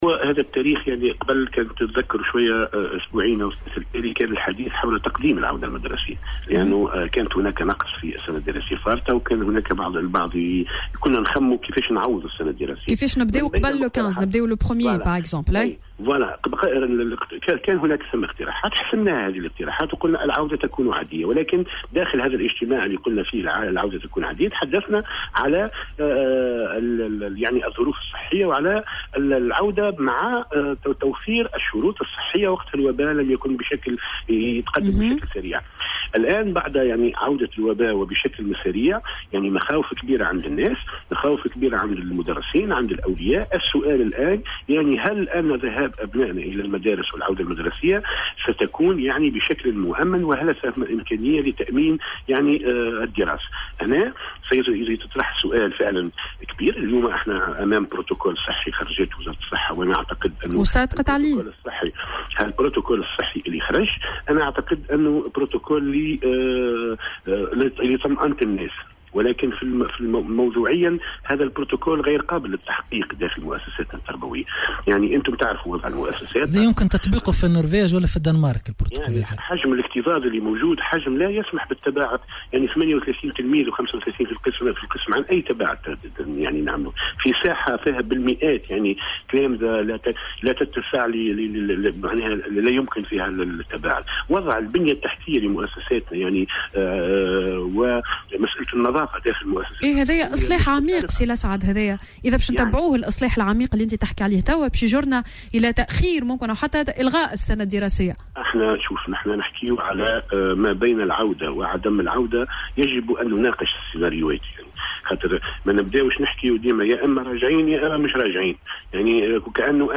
وأضاف في مداخلة هاتفية مع "صباح الورد" على "الجوهرة أف أم" أنه بعد الانتهاء من الحجر الصحي الذاتي الذي تقرر القيام به اثر اكتشاف اصابة أحد النقابيين بكورونا على هامش أشغال المجلس الوطني لاتحاد الشغل في الحمامات، سيتم مناقشة مسألة العودة غير الممكنة حاليا في ظل تسارع تفشي كورونا في البلاد وعدم توفّر ظروف الوقاية بالمدارس. وأكد أن البرتوكول الصحي الذي تم وضعه لا يمكن تطبيقه على أرض الواقع بالمدارس حيث يقدّر مثلا معدّل عدد التّلاميذ في القسم الواحد بأكثر من 35 تلميذا.